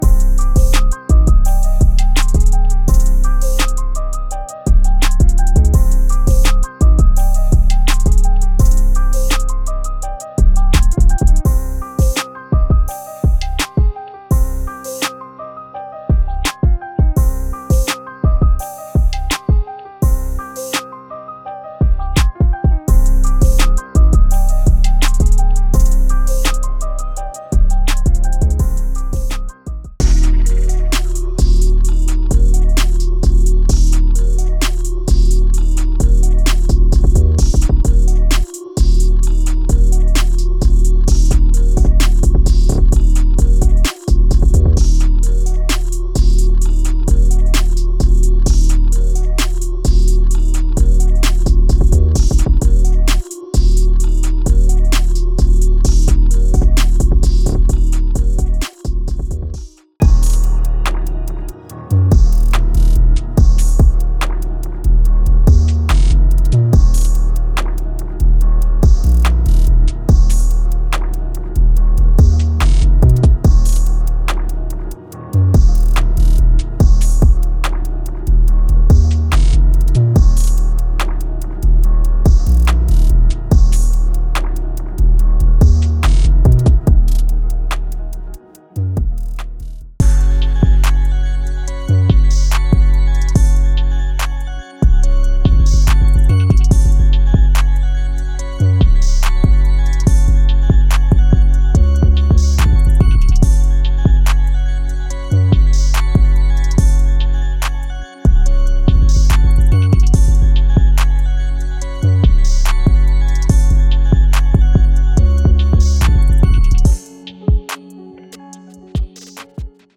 BPM 130